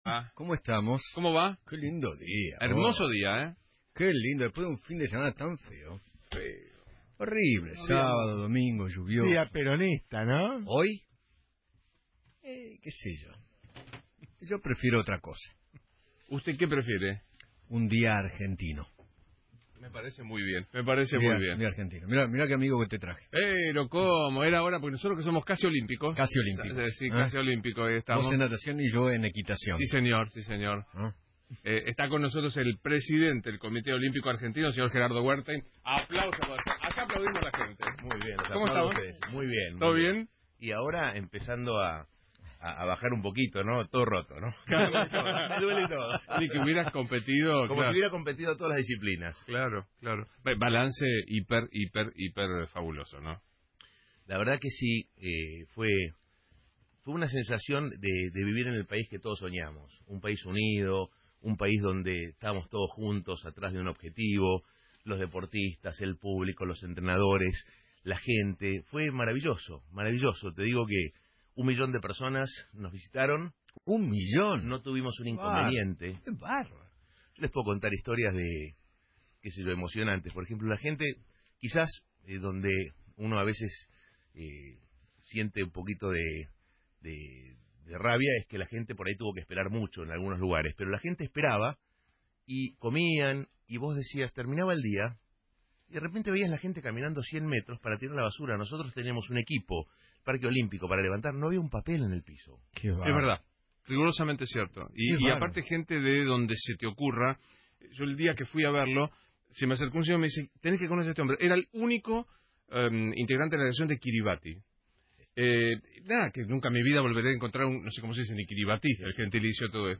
Recibimos la visita de Gerardo Werthein, Presidente del Comité Olímpico Argentino - Eduardo Feinmann